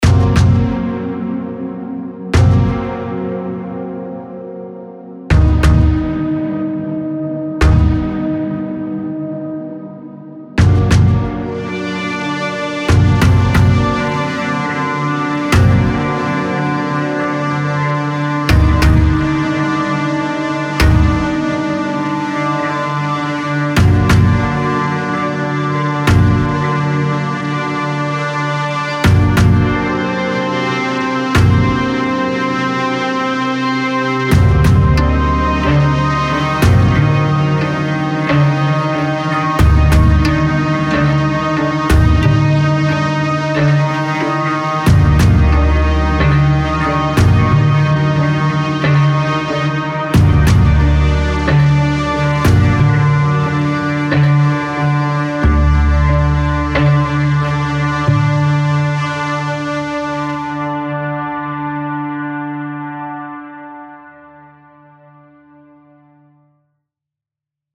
Modern Score